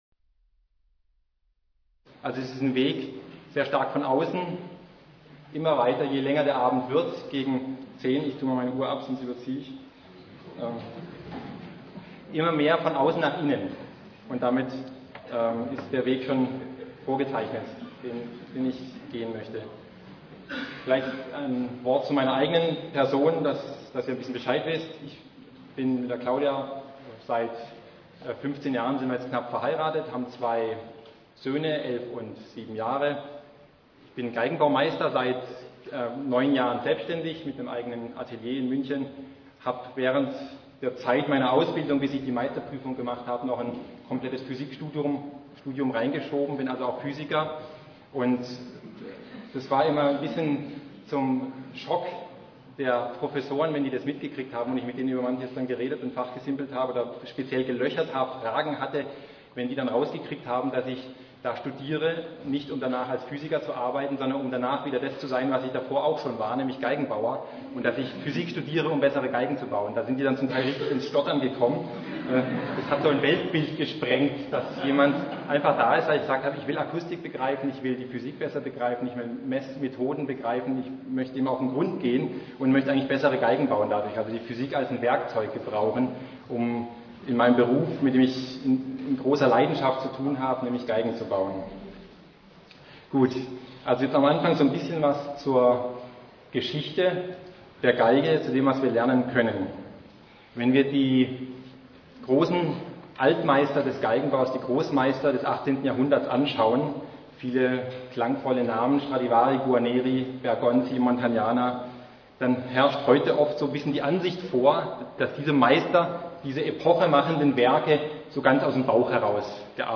Die Geige – eine Vision Vortrag